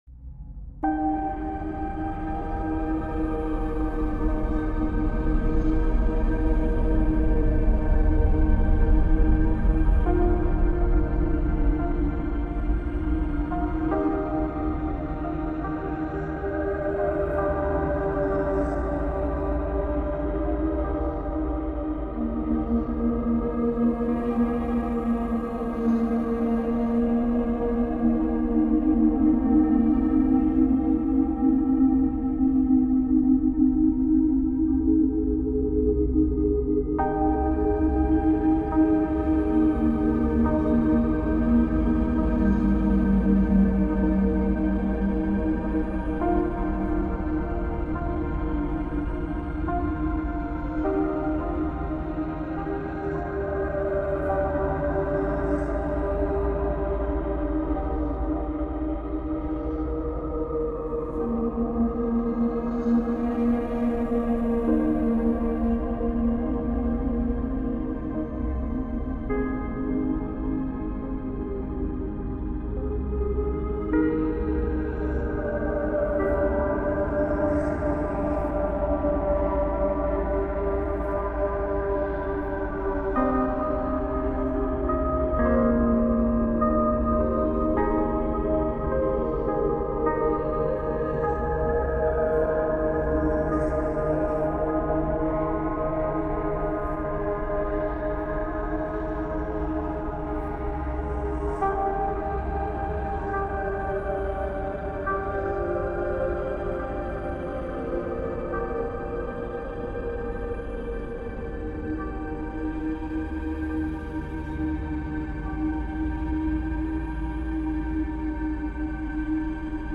notes de synthétiseur